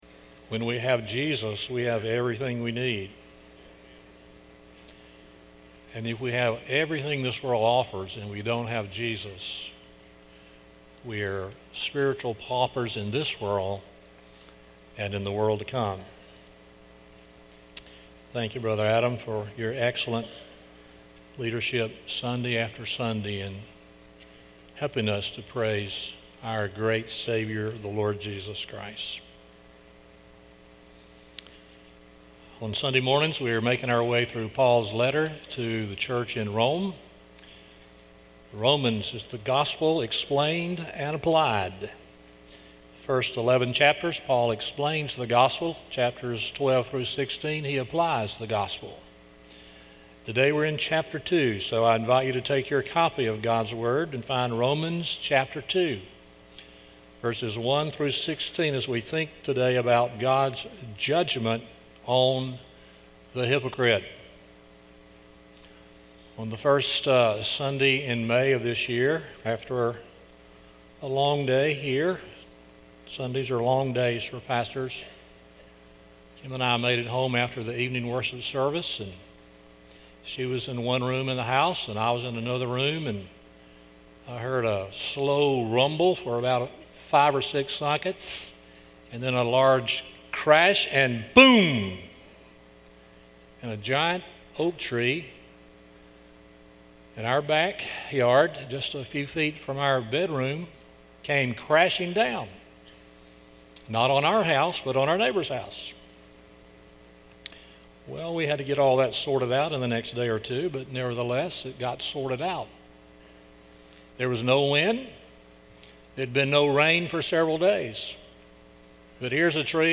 Romans 2:1-16 Service Type: Sunday Morning 1.